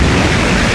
immolatorShoot.ogg